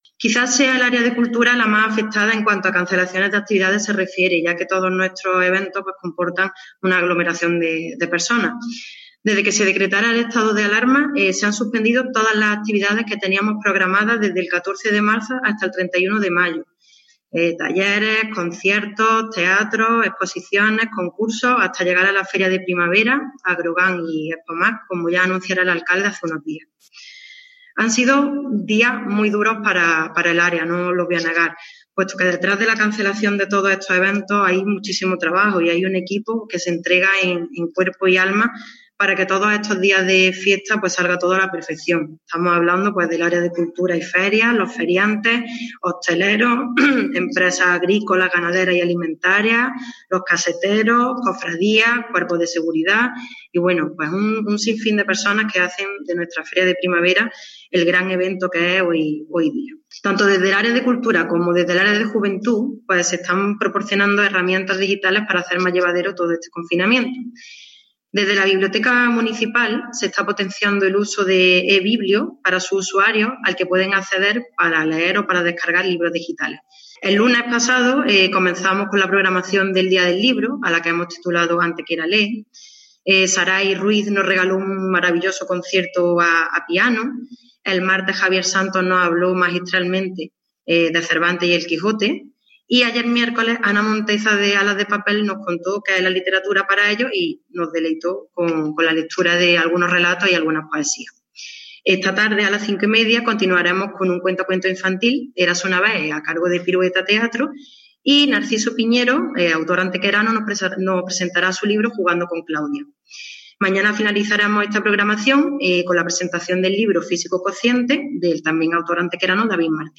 Unos versos del celebérrimo poeta antequerano José Antonio Muñoz Rojas iniciaban en la mañana de hoy jueves 23 de abril, Día del Libro, la rueda de prensa que tanto el alcalde de Antequera, Manolo Barón, como la teniente de alcalde Elena Melero han protagonizado para ofrecer un balance de actividad del Área de Cultura, Ferias, Tradiciones y Juventud durante el periodo de Estado de Alarma que aún sigue vigente.
Cortes de voz